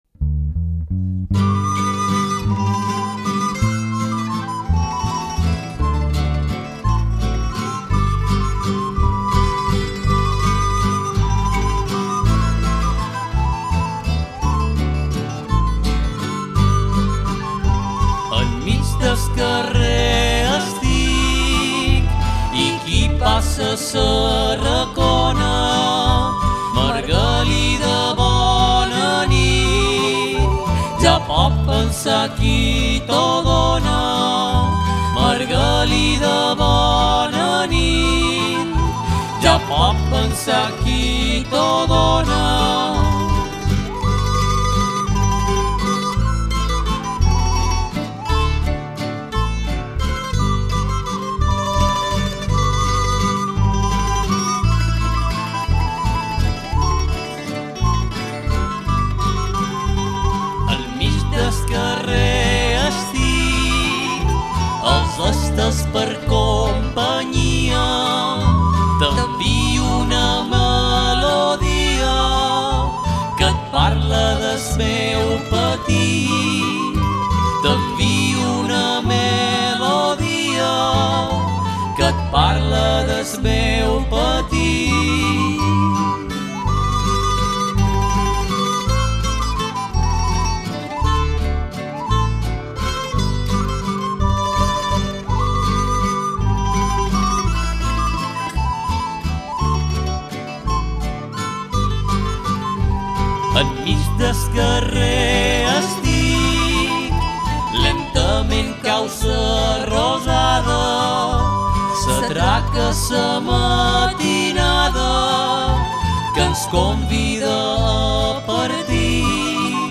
Tra le canzoni che si cantano la sera delle Vergini ce n'è una specialmente interessante, perché tipica del mio paese. Ecco a voi la versione che interpreta il Gruppo di Cultura Tradizionale Sarau Alcudienc.
32-Valset-alcudienc-.mp3